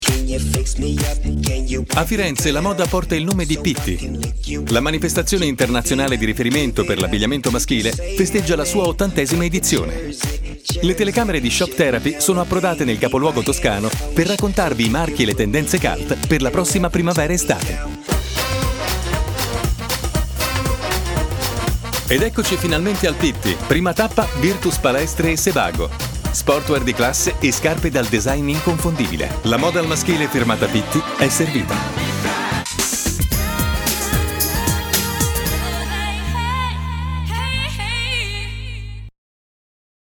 Demo
Romagnolo, emiliano
Voce duttile e di ampia gamma.